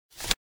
TW_Unlock_Glass.ogg